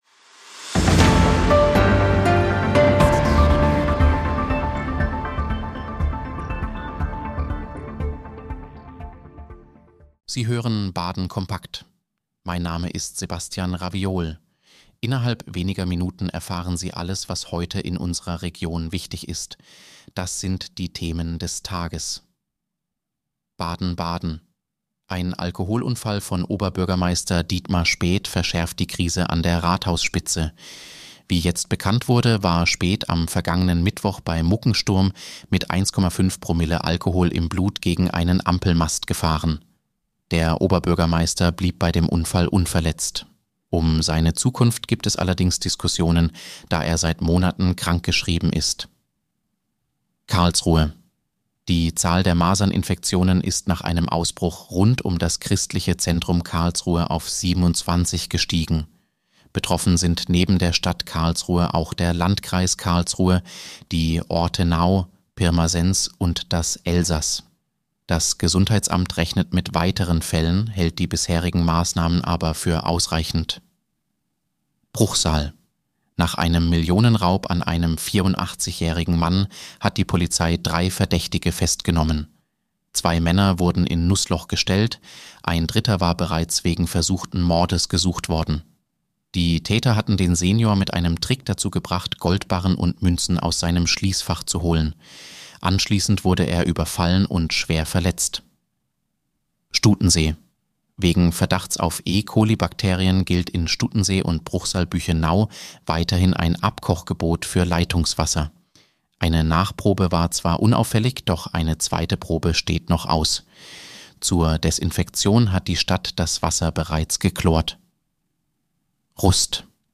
Nachrichtenüberblick Montag, 10. November 2025